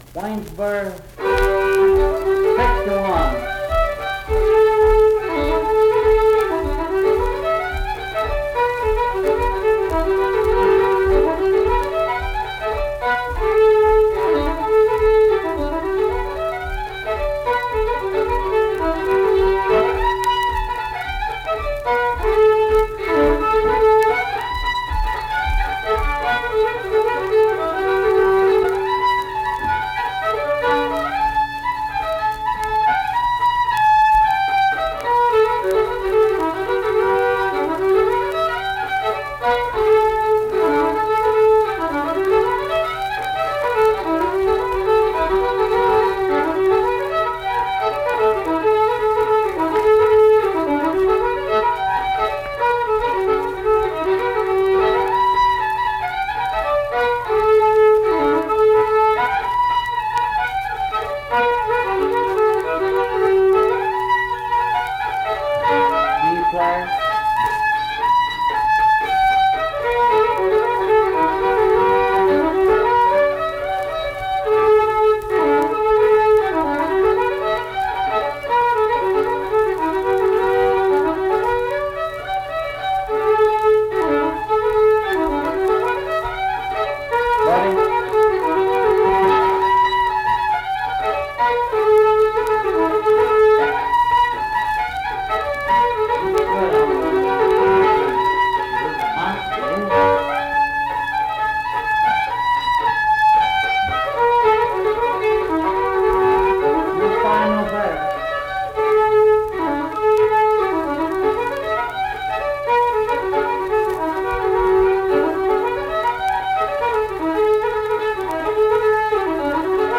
Accompanied guitar and unaccompanied fiddle music performance
Guitar accompaniment
Instrumental Music
Fiddle